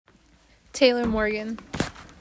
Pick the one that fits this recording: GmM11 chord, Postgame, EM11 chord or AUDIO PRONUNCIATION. AUDIO PRONUNCIATION